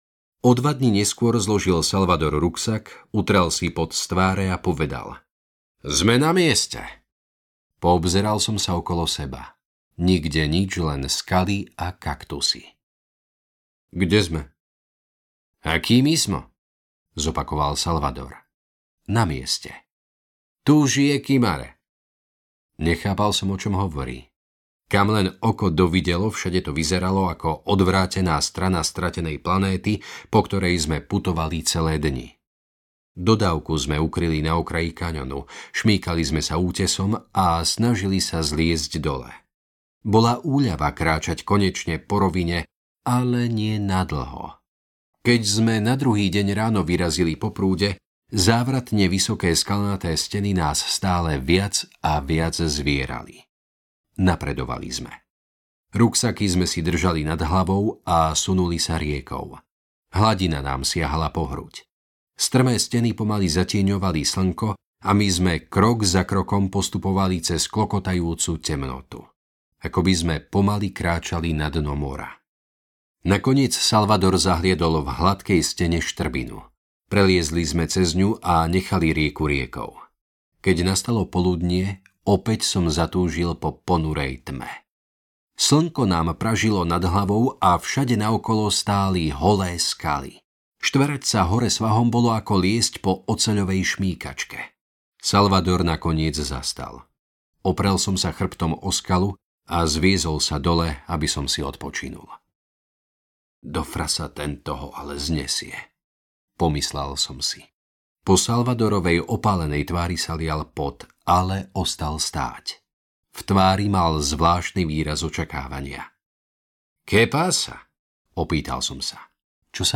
Audiokniha Stvorení pre beh - Christopher McDougall | ProgresGuru